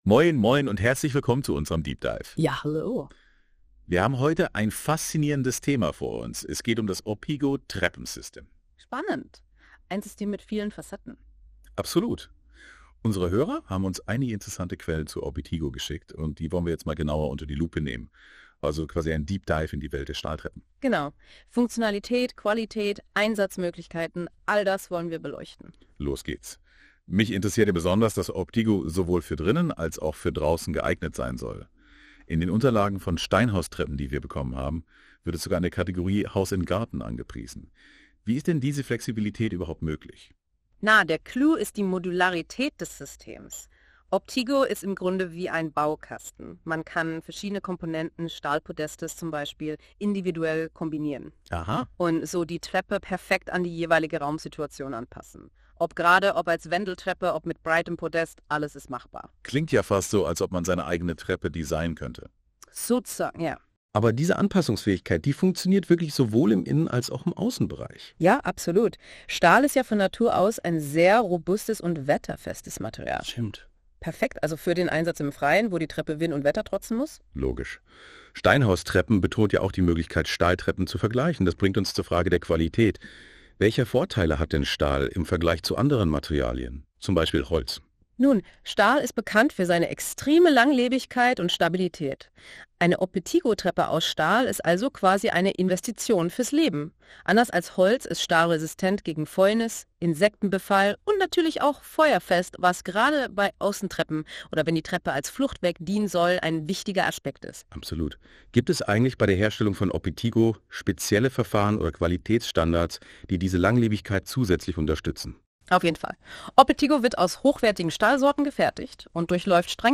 Lass Dir in einem kurzen Überblick die Modulbauweise unseres Stahltreppen Systems erläutern. Ein Podcast über Außentreppen in einem informativen Gespräch über unser Treppensystem OPTIGO, fachlich und kompetent erklärt.
Dokumentation in deutscher Sprache, KI-generiert von Google NotebookLM, ungeschnitten.